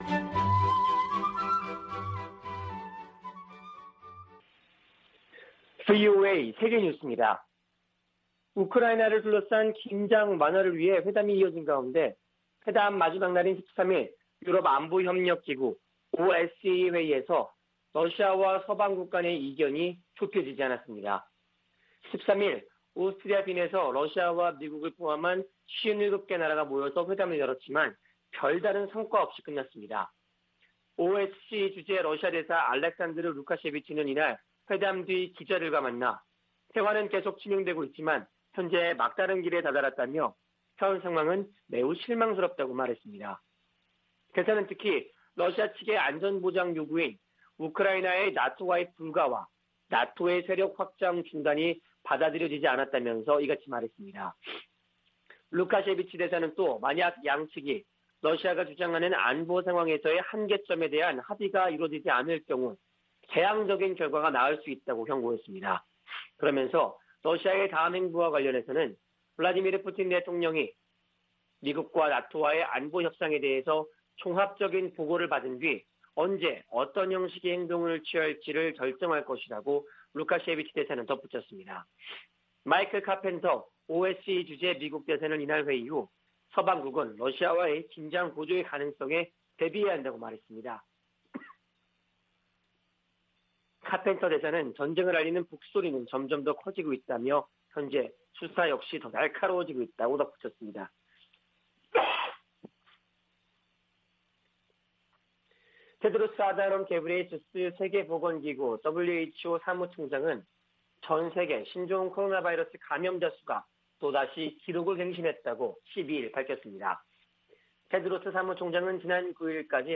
VOA 한국어 아침 뉴스 프로그램 '워싱턴 뉴스 광장' 2021년 1월 14일 방송입니다. 미국이 북한 탄도미사일 발사에 대응해 유엔에서 추가 제재를 추진합니다. 미국 정부가 북한 미사일 관련 물품을 조달한 북한 국적자 6명과 러시아인 등을 제재했습니다. 북한이 김정은 국무위원장 참관 아래 극초음속 미사일 시험발사 성공을 발표하면서 미-북 간 갈등이 고조되고 있습니다.